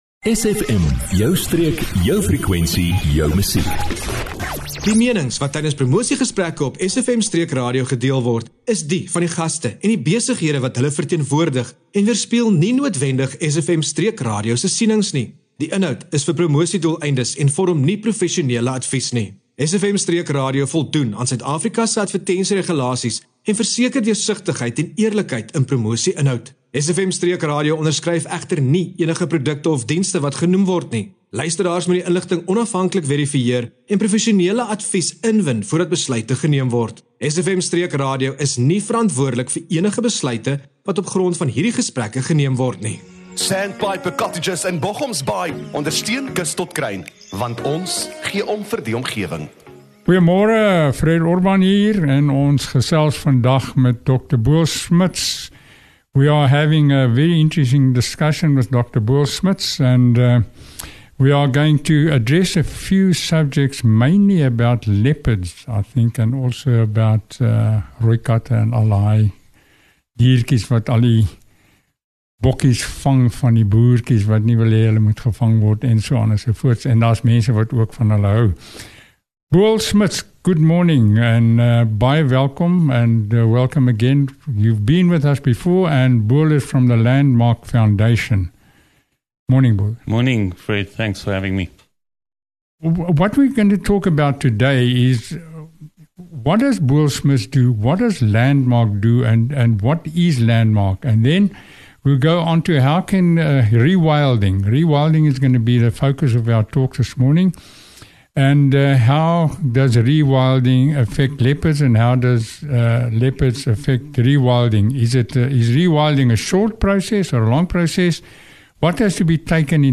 Die gesprek fokus op hoe samewerking en bewuste bewaringsinisiatiewe die laaste wildheid in ons landskap kan red. 🎧 Don’t miss this inspiring interview on SFM Streek RADIO – where conservation meets action.